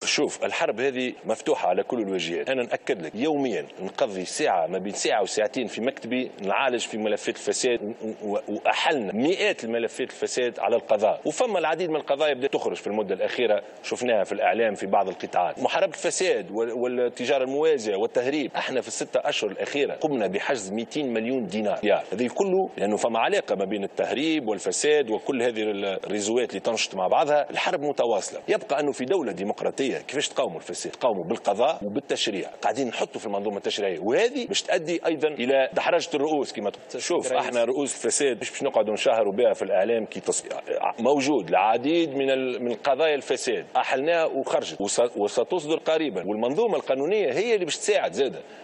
أكد رئيس الحكومة يوسف الشاهد في حوار خاص مع قناة الحوار التونسي، أن حكومته حققت عدة نجاحات في 6 أشهر، أهمها تحسن الوضع الأمني والنمو والاقتصاد، مضيفا أن من أساسيات عمل الحكومة، خلق الثقة بين الشعب والدولة، وخاصة مع الفئة الشابة.